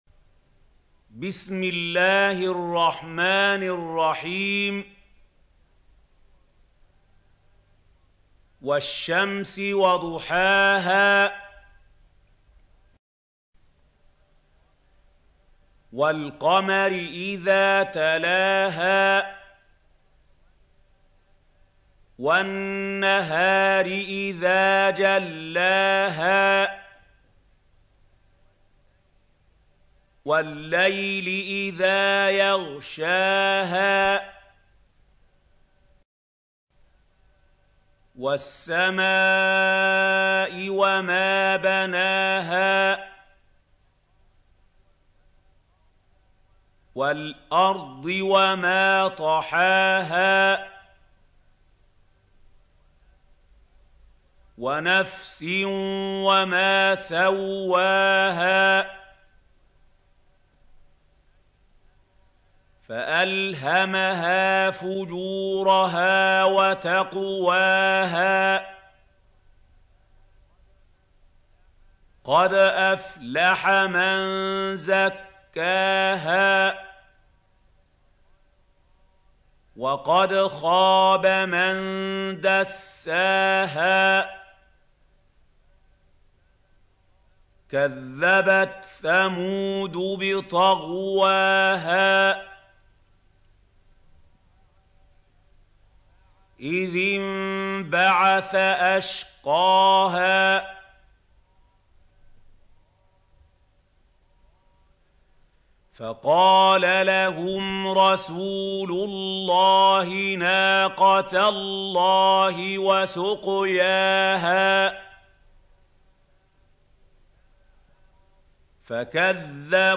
سورة الشمس | القارئ محمود خليل الحصري - المصحف المعلم